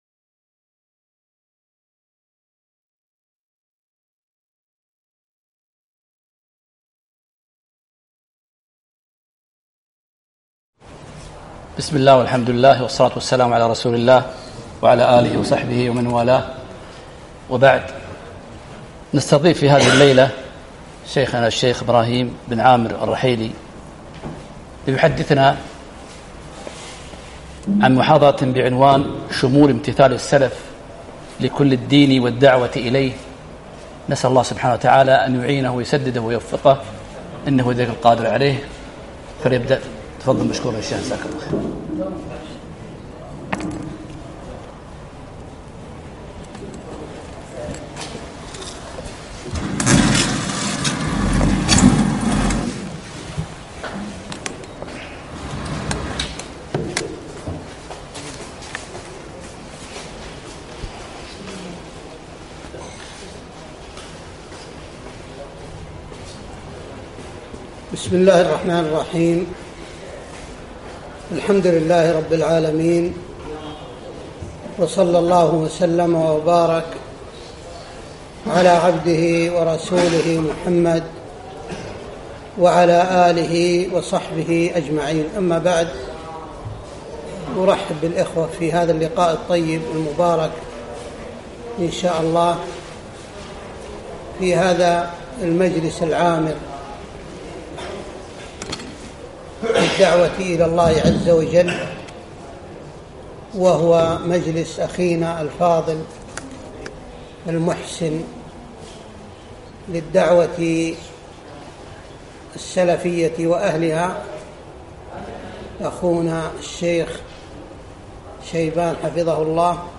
محاضرة - شمول امتثال السلف لكل الدين والدعوة إليه